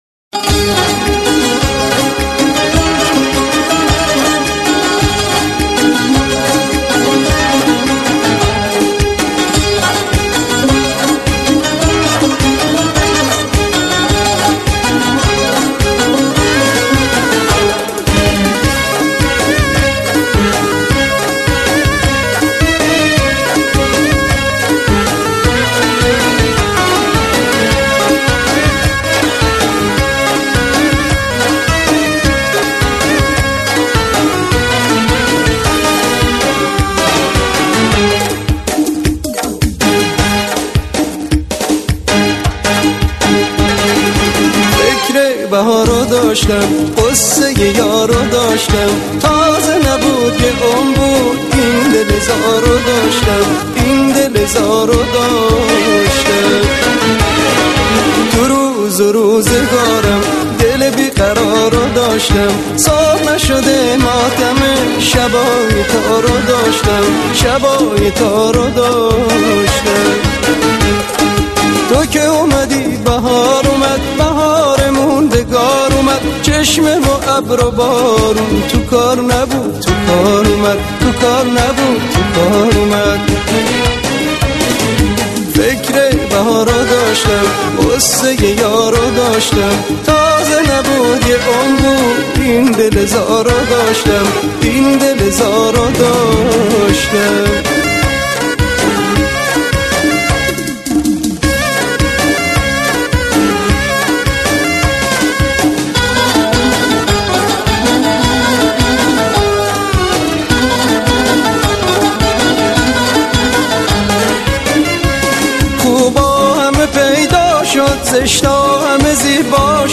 خوانندهٔ سرشناس موسیقی پاپ ایرانی بود.
صدای محزون و دلنشینی داشت.